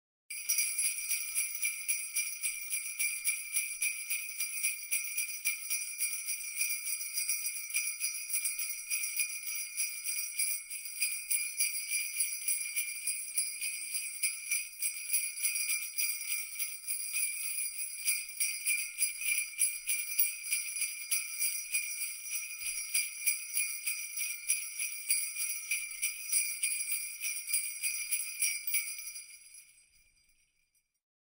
Jingle Bells Sound Effect - Online Free MP3 Download
Jingle-Bells-Sound-Effect-for-editing-128kbps.mp3